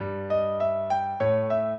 minuet14-8.wav